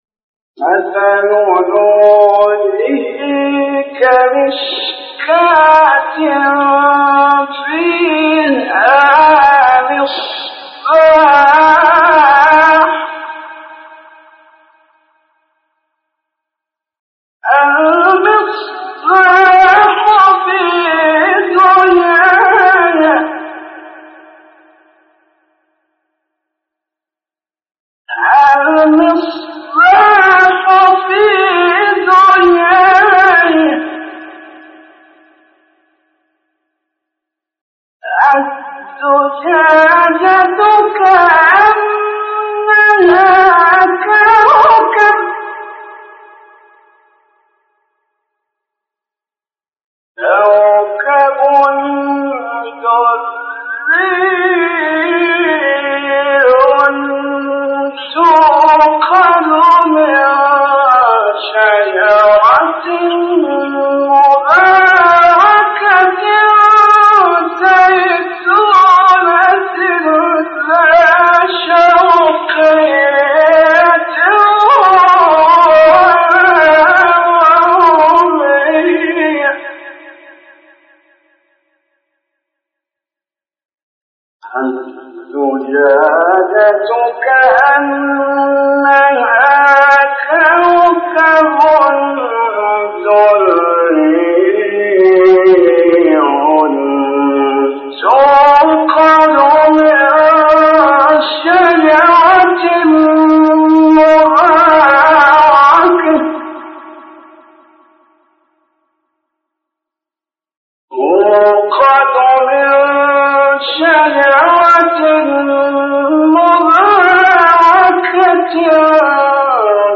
آیه 35 سوره نور استاد محمد رفعت | نغمات قرآن | دانلود تلاوت قرآن